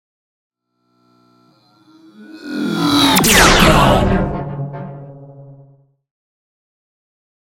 Sci fi shot whoosh to hit
Sound Effects
futuristic
intense
woosh to hit